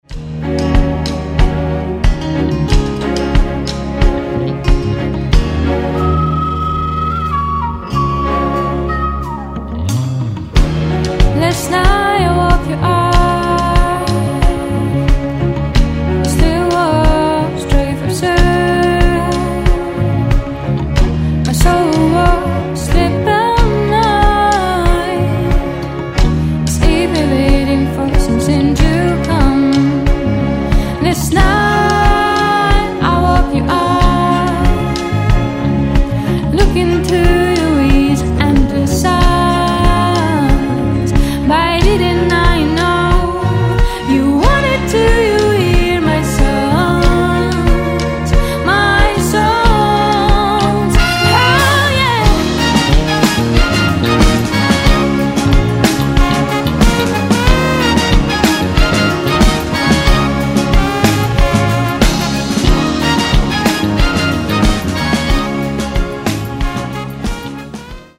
Каталог -> Рок та альтернатива -> Ліричний андеграунд
Простір – ось чим бере за душу цей альбом.